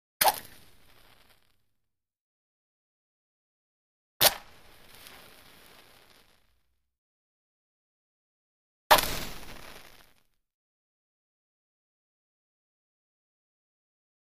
Match Lights - 3 Effects; Various Match Strikes.